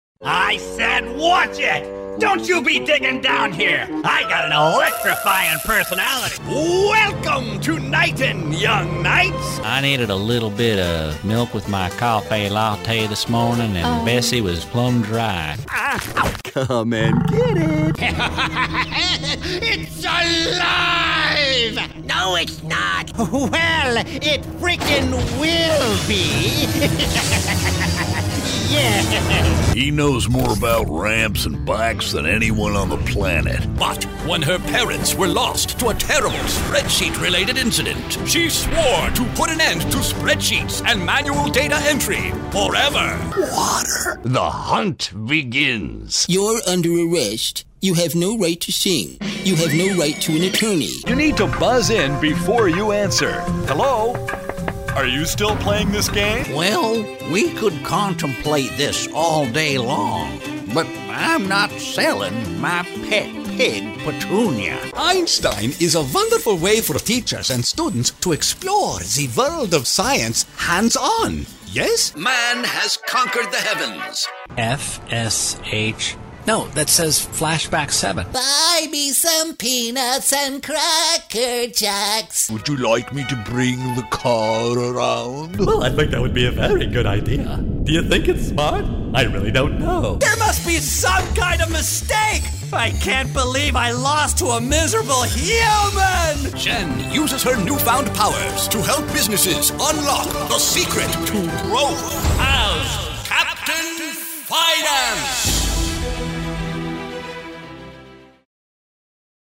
Free voice over demos.